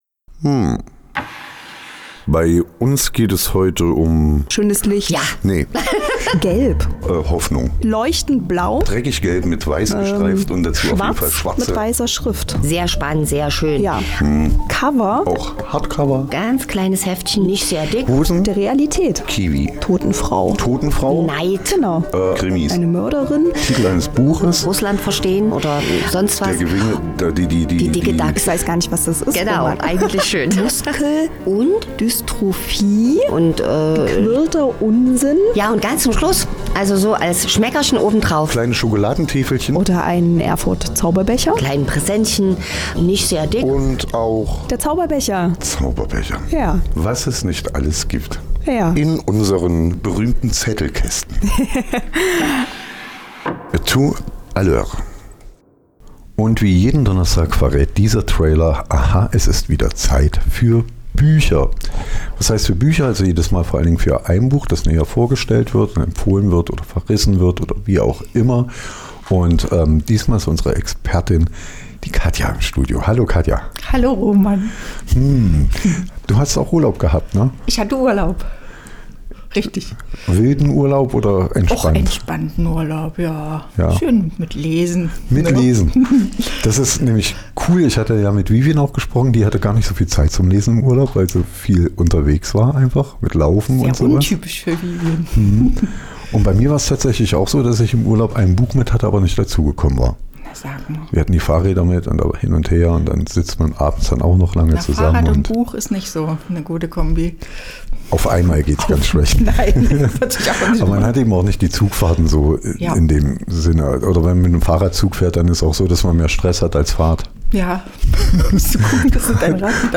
Zettelkasten - die aktuelle Buchbesprechung | Charlotte Brandi - Fischtage